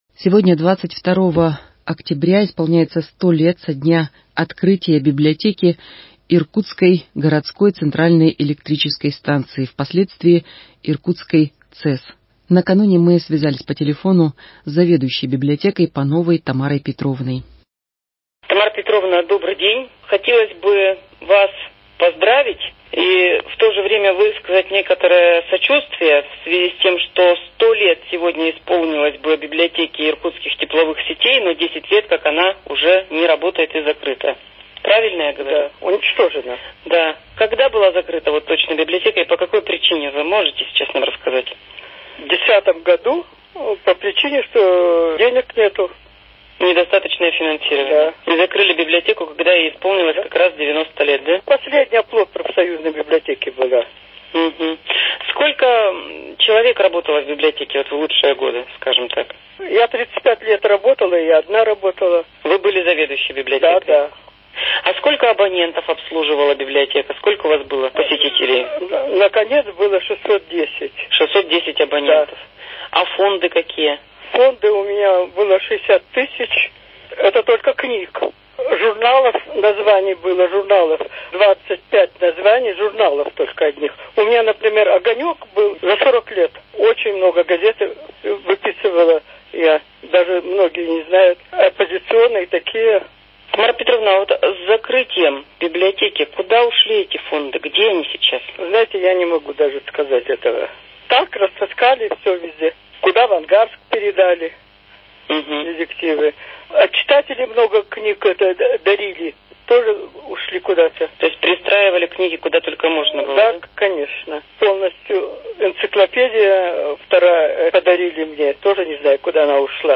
К 100-летию со дня основания библиотеки Иркутской городской центральной электрической станции - интервью по телефону